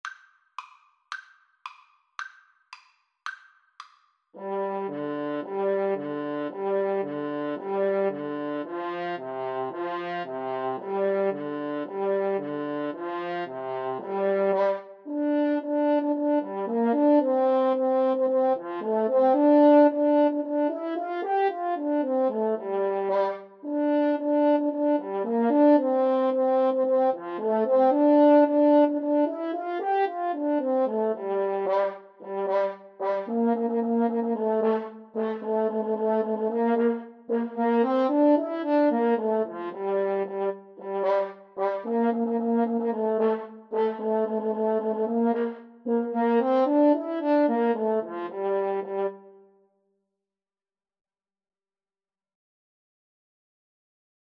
Allegro moderato = c. 112 (View more music marked Allegro)
2/4 (View more 2/4 Music)